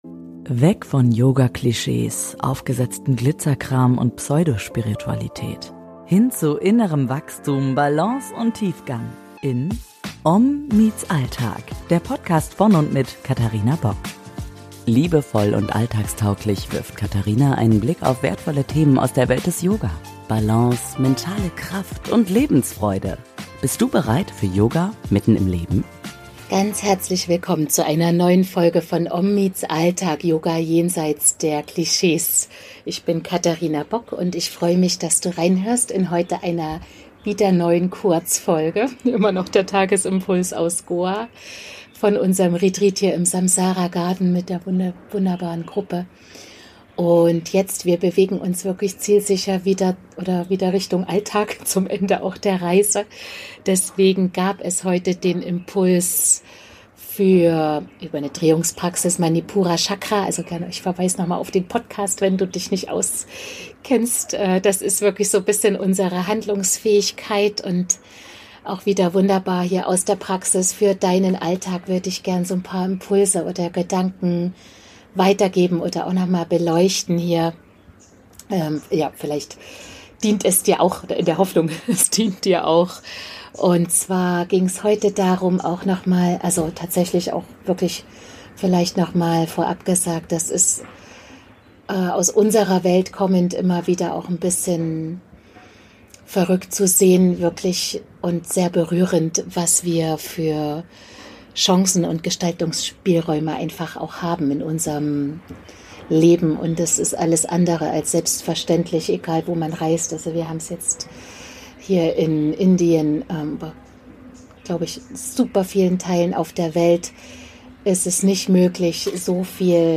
Beschreibung vor 3 Tagen In dieser OM TO GO-Folge aus dem Retreat im Samsara Garden in Goa richtet sich der Blick langsam zurück in den Alltag – und auf deine Gestaltungskraft. Im Fokus: Manipura Chakra, das Energie- und Feuerzentrum im Oberbauch, Sitz von Handlungskraft, Mut und Selbstvertrauen.